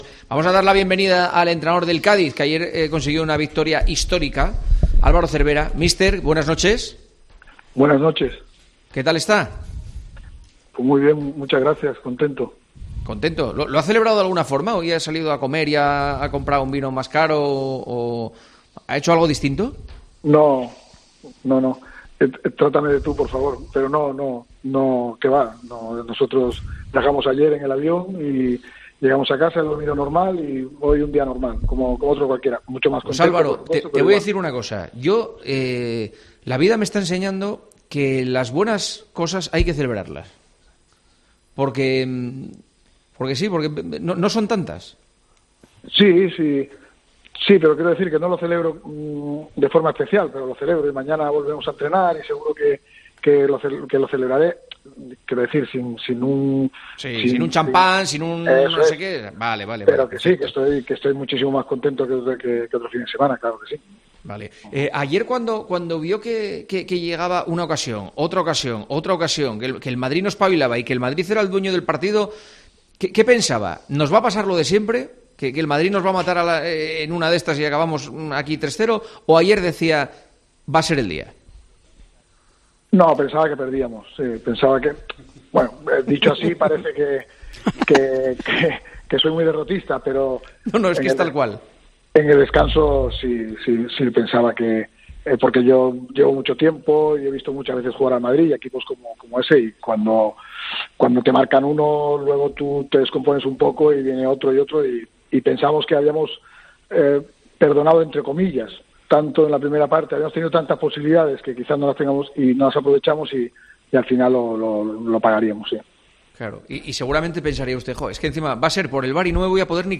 Álvaro Cervera habla de la victoria ante el Real Madrid en Tiempo de Juego